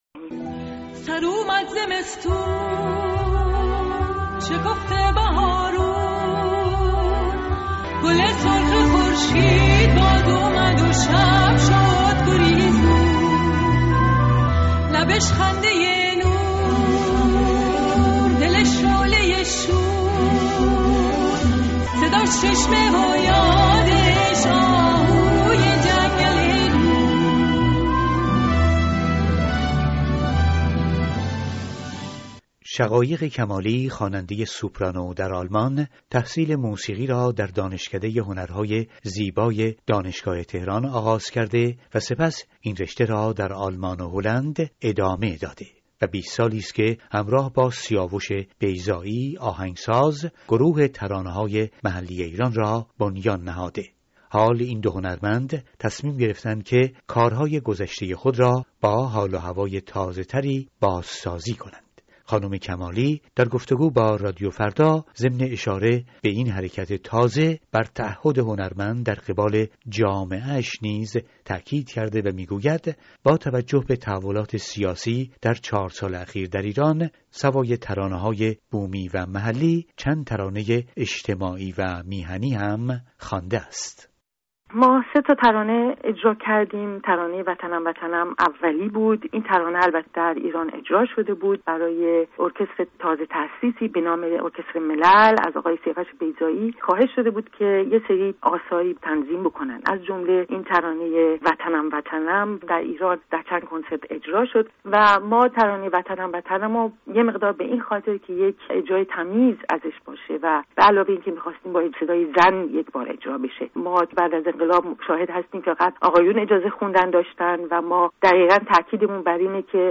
گپی
گفتگوی